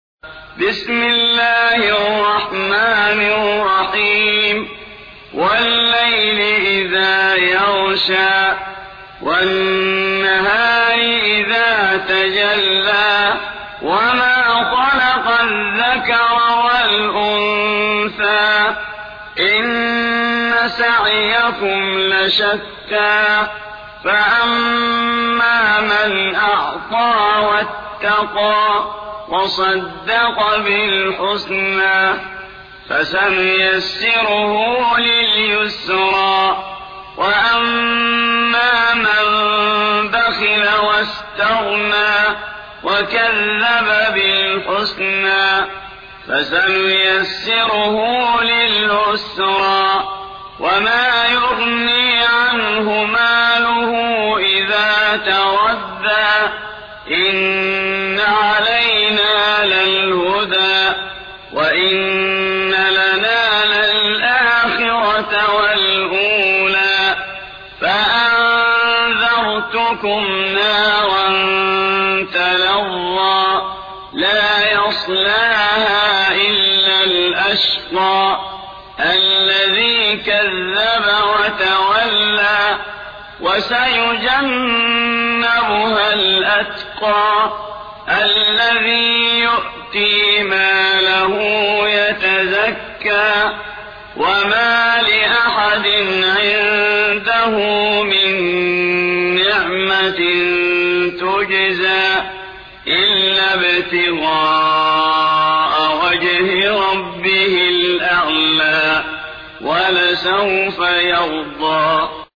92. سورة الليل / القارئ